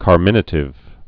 (kär-mĭnə-tĭv, kärmə-nā-)